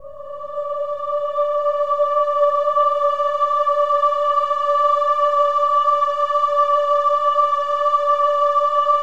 OH-AH  D5 -R.wav